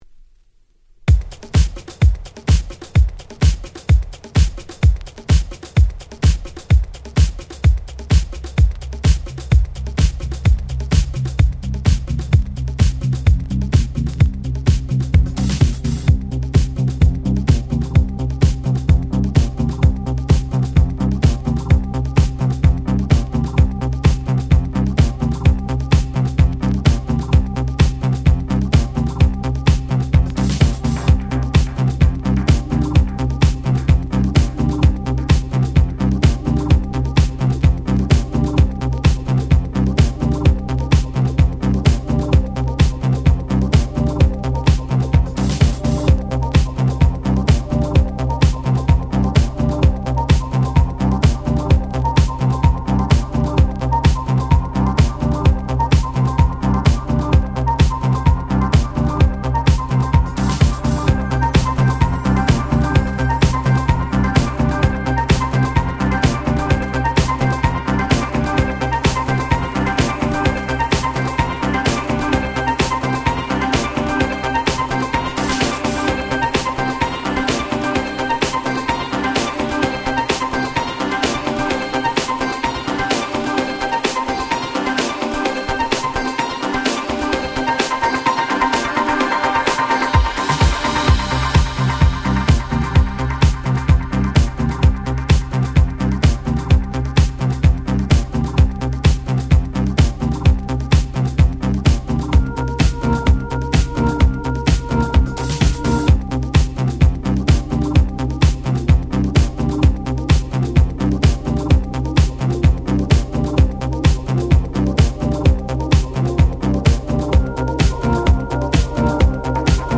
17' Very Nice Re-Edit !!